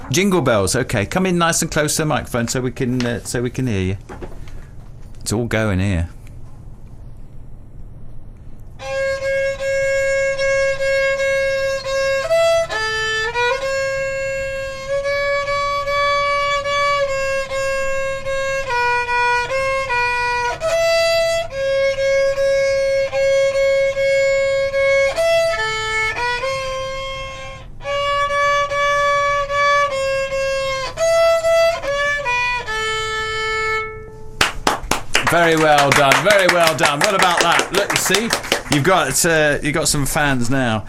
The caller featured in this trail is a great example of the value of local radio